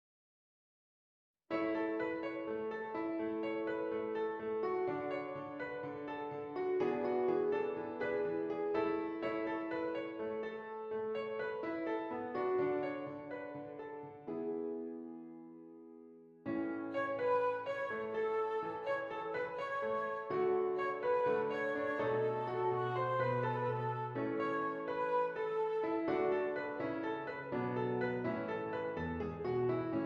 Flute Solo with Piano Accompaniment
Does Not Contain Lyrics
A Major
Moderately fast